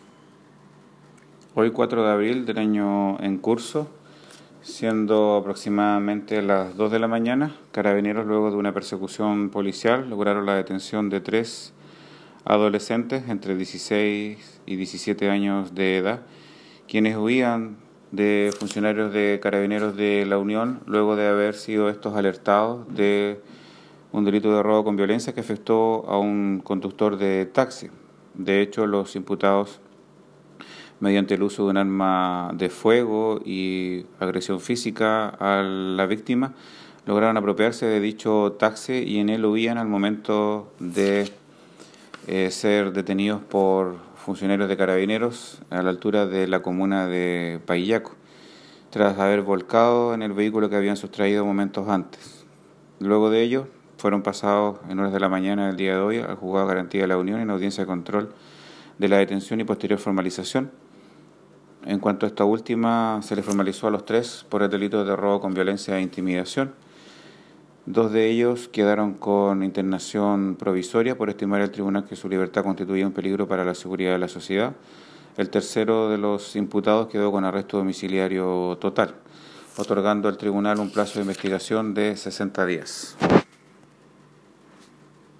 El fiscal Raúl Suárez sobre la formalización efectuada esta tarde por la Fiscalía a tres adolescentes imputados por un delito de robo con violencia e intimidación que cometieron esta madrugada en la comuna de La Unión, al agredir al conductor de un taxi para sustraerle el vehículo, el que posteriormente volcaron en la comuna de Paillaco. Dos de los imputados quedaron privados de libertad con internación provisoria y el tercero quedó con arresto domiciliario nocturno.